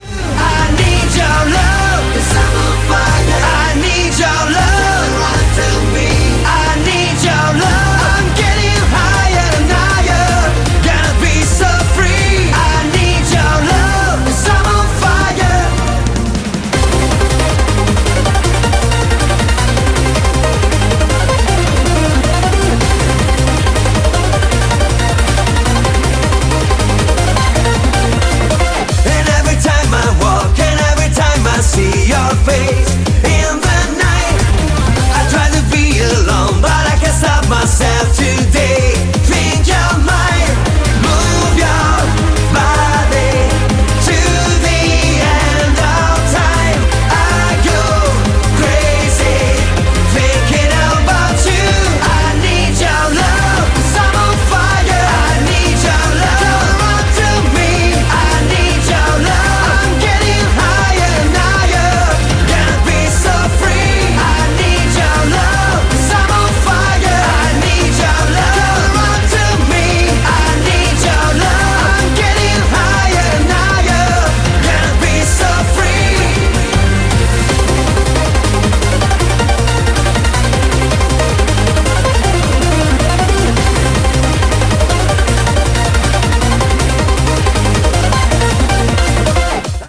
BPM158
Audio QualityPerfect (High Quality)
CommentsAnother eurobeat treat from yours truly.